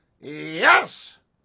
wack_yeeeeessss.wav